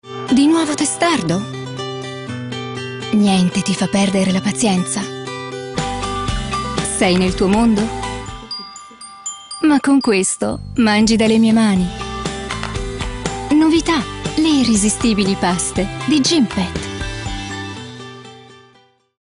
Sprechprobe: Sonstiges (Muttersprache):
I am an educated actress and I speak with a broad italian accent, what makes my speach very clear and articulately.
I have a joung, beautiful and light voice.